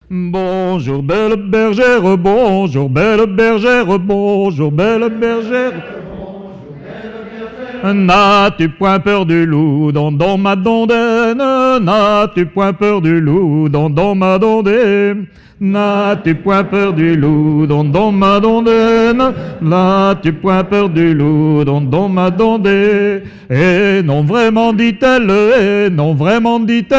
chansons traditionnelles lors d'un concert associant personnes ressources et continuateurs
Pièce musicale inédite